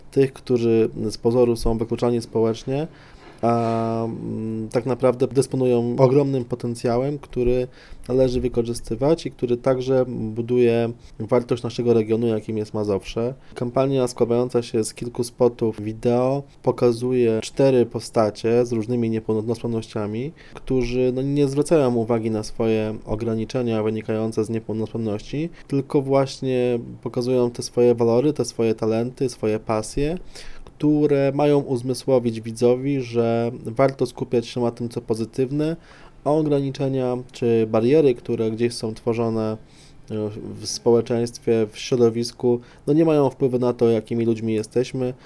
Pełnomocnik zarządu województwa ds. osób z niepełnosprawnościami, Artur Świercz mówi, że ta akcja ma pokazać tę grupę osób jako tych, którzy mają ogromny potencjał do tego, aby wieść aktywne życie społeczne.